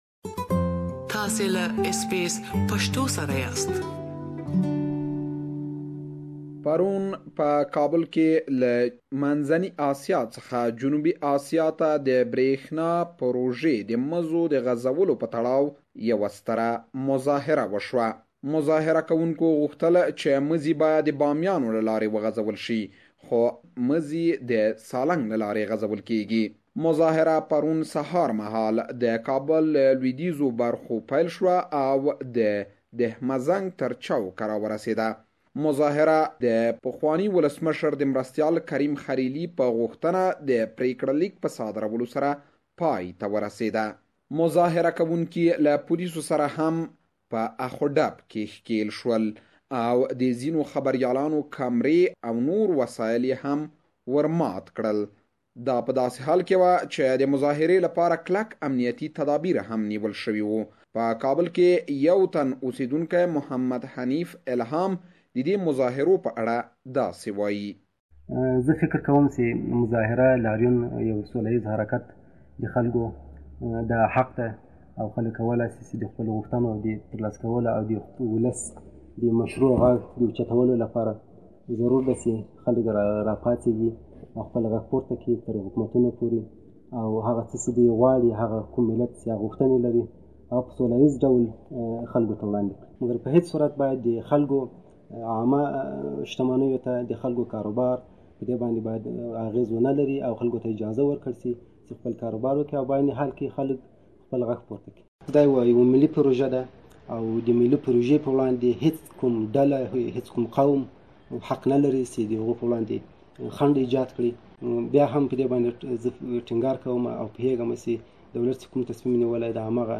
Kabul city was like a city in lockdown yesterday and the protest ended without any significant incident. We have interviewed Kabul resident and an activist for their thoughts about yesterdays protest. Please listen to their interviews here.